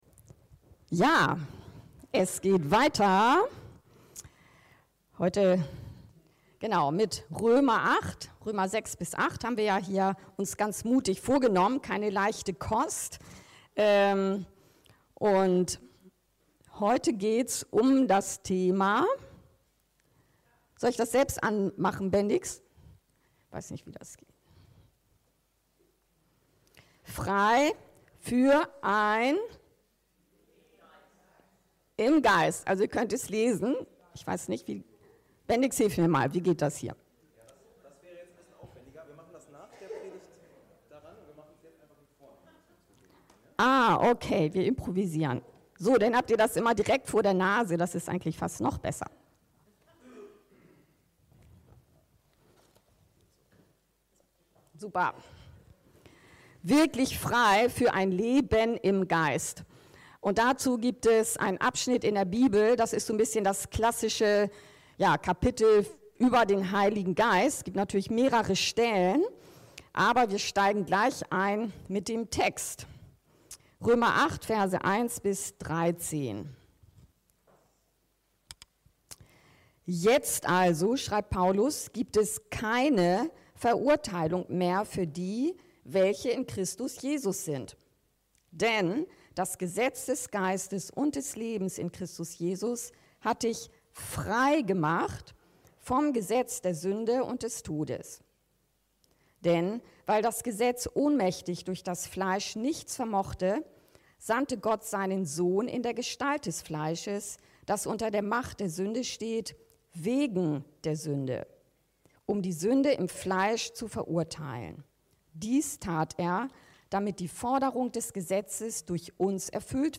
Wirklich Frei für ein Leben im Geist ~ Anskar-Kirche Hamburg- Predigten Podcast